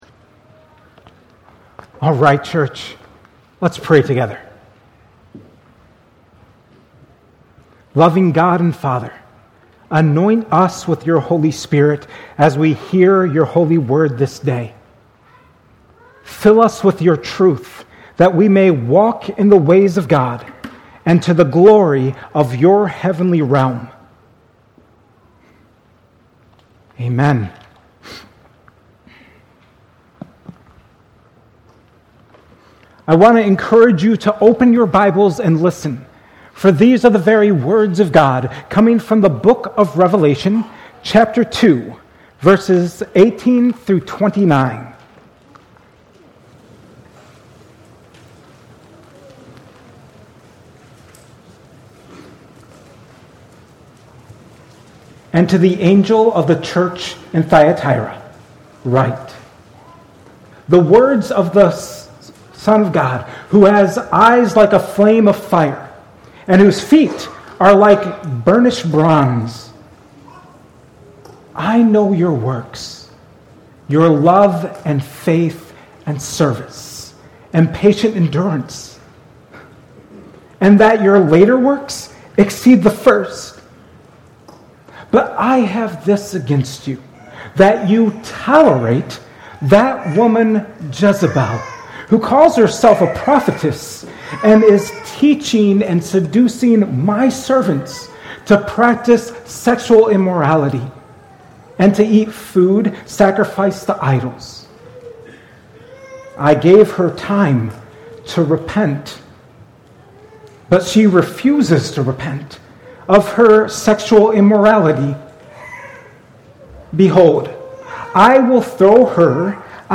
The Seven: Thyatira - Message from Cornerstone Church